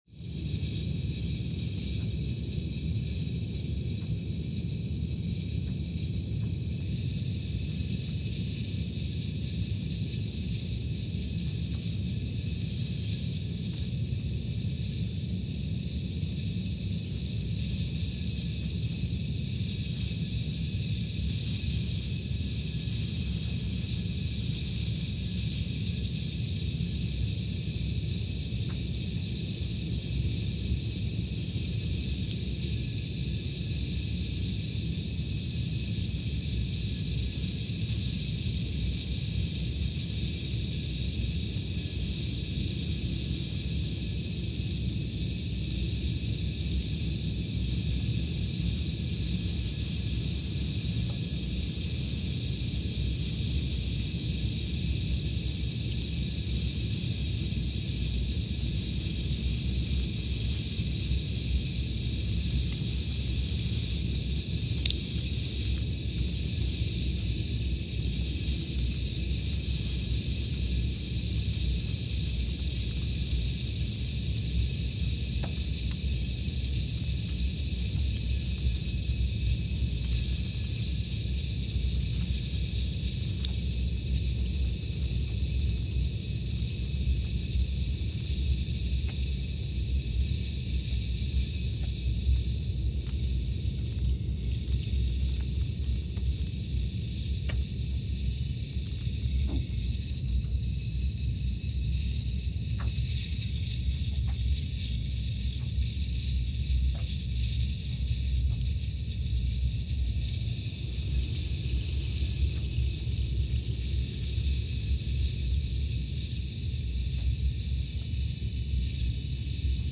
Scott Base, Antarctica (seismic) archived on July 4, 2022
No events.
Station : SBA (network: IRIS/USGS) at Scott Base, Antarctica
Sensor : CMG3-T
Speedup : ×500 (transposed up about 9 octaves)
Loop duration (audio) : 05:45 (stereo)